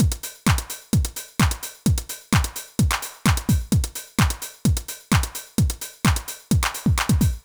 INT Beat - Mix 4.wav